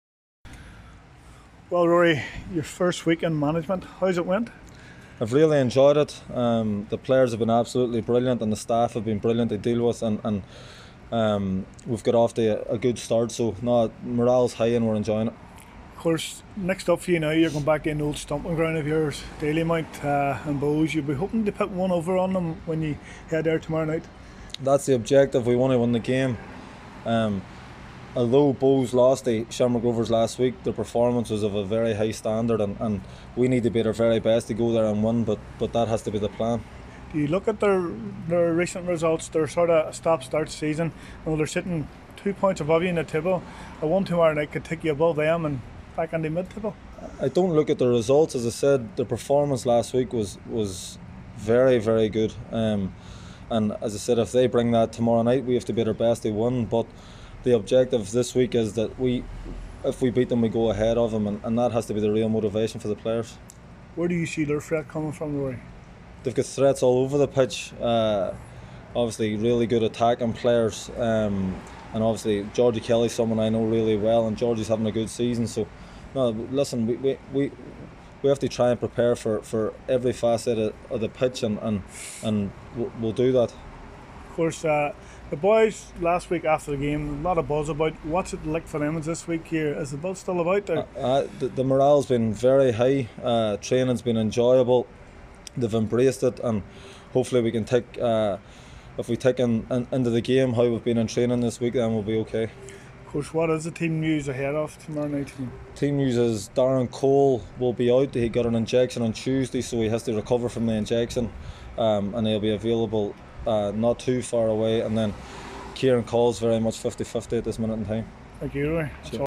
caught up with Derry City manager Ruaidhrí Higgins at the Brandywell this morning.